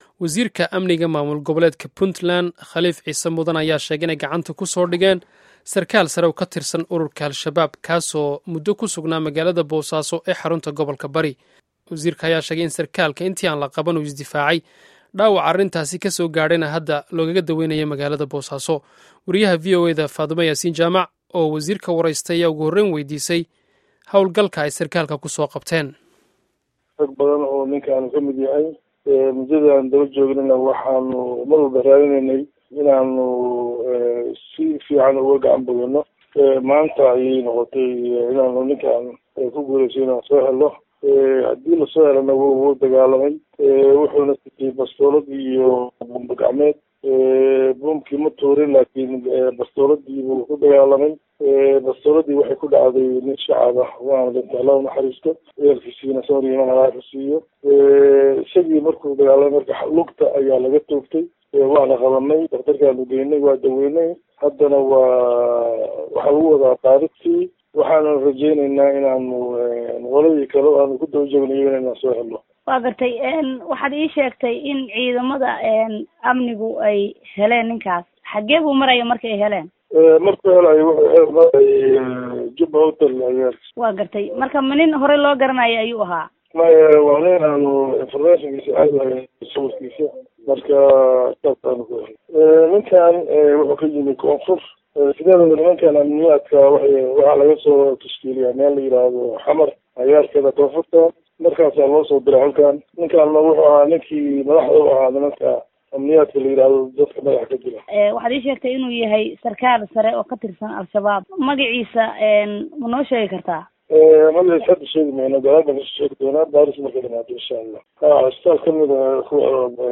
Wareysiga Wasiirka Amniga Puntland